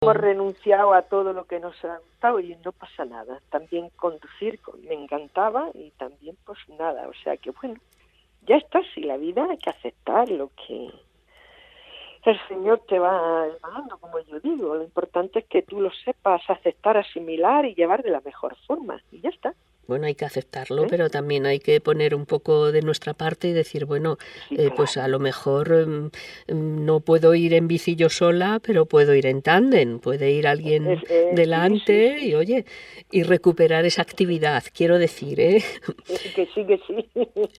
Sensibilidad a flor de piel cuando vibra también su voz mientras echa la vista atrás y se detiene en aquel día, el 17 de abril de 2017, que le “encandiló el sol” y dejó de ver como lo había hecho hasta entonces.